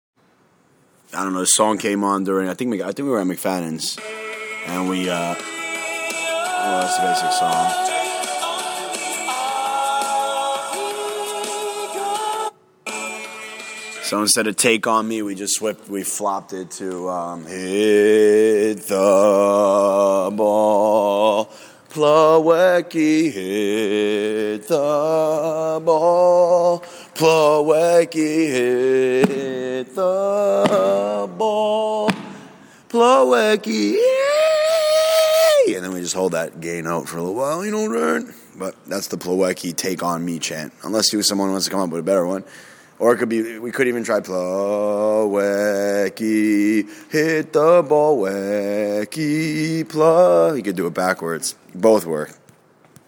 plawecki on me chant